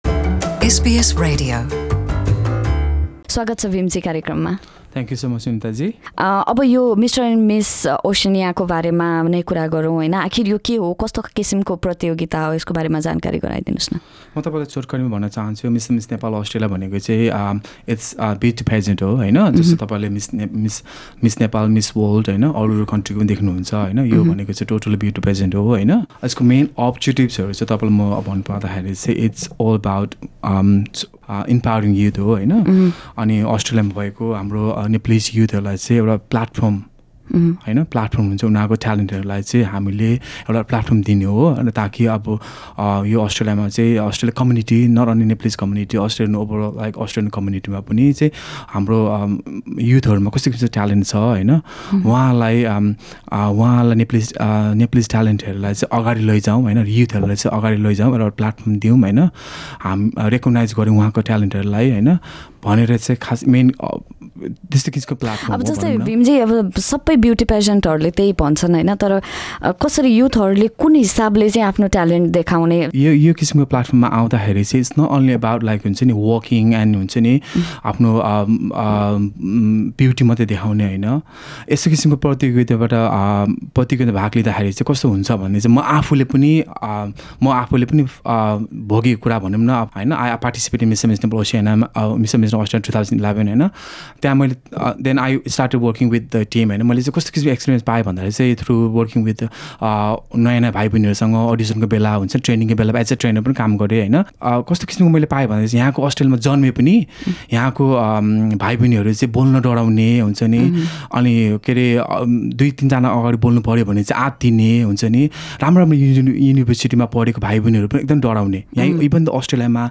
आगामी डिसेम्बरमा सिड्नीमा आयोजना हुन लागेको सुन्दरता प्रतियोगितामा अस्ट्रेलिया लगायत न्यूजिल्याण्ड र फिजीका नेपालीहरुको पनि सहभागिता रहने आयोजकहरुको भनाइ छ। एसबीएस नेपालीसंगको कुराकानीमा